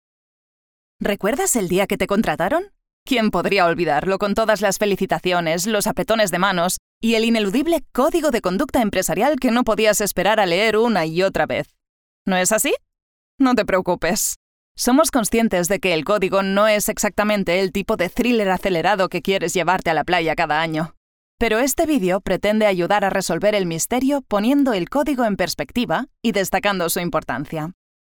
Warm, Soft, Natural, Friendly, Young
E-learning